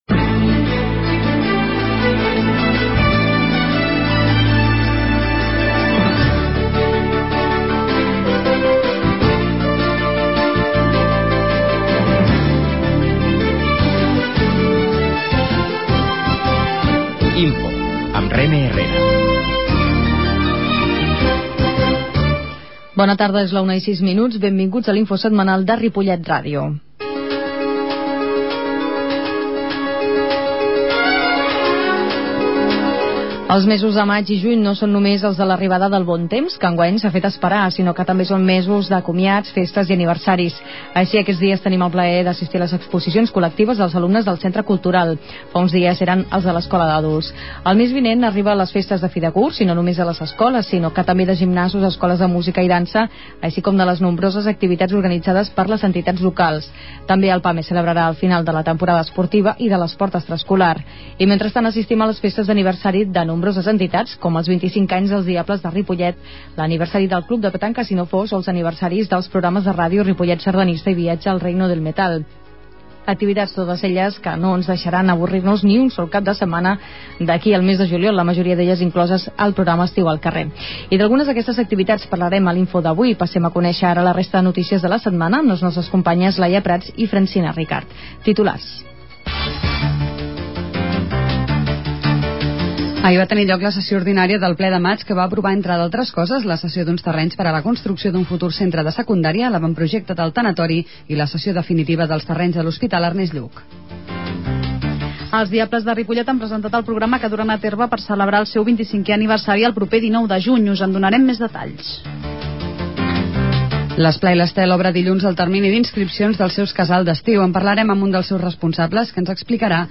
La qualitat de so ha estat redu�da per tal d'agilitzar la seva baixada.